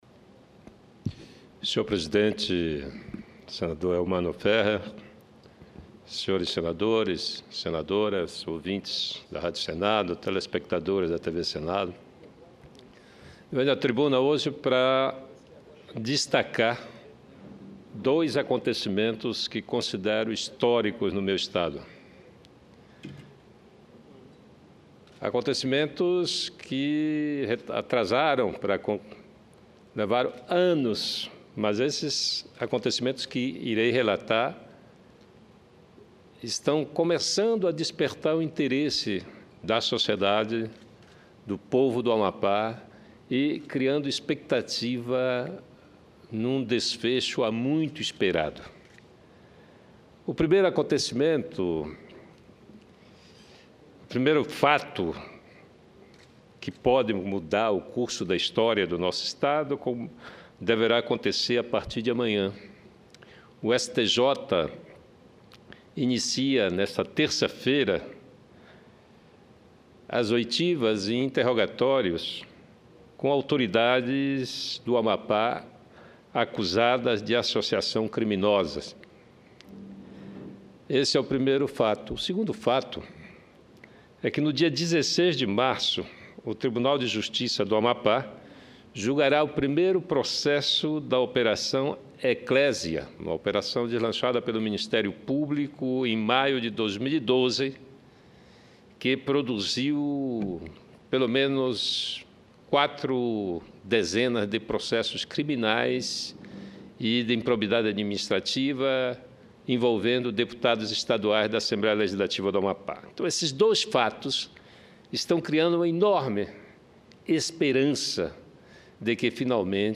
Discuros